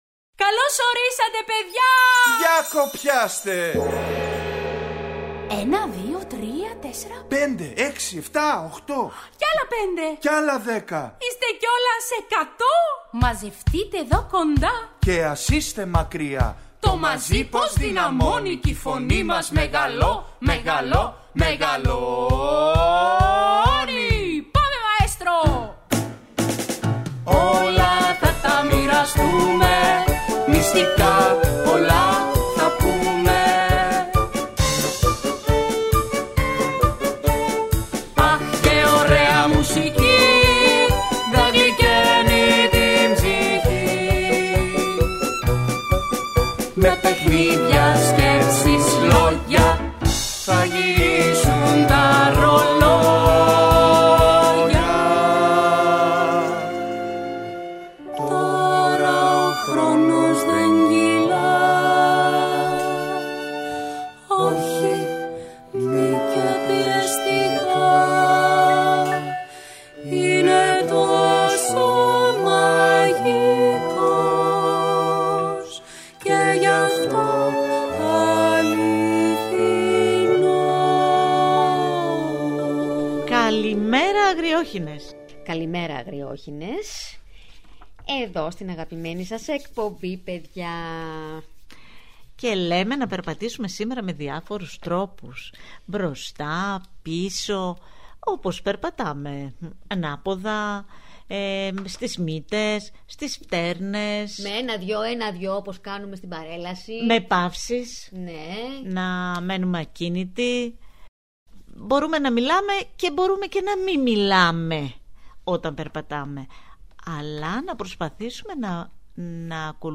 Ακούστε στην παιδική εκπομπή ‘’Οι Αγριόχηνες’’ το παραμύθι «Τα Ελληνάκια» της Ευγενίας Φακίνου.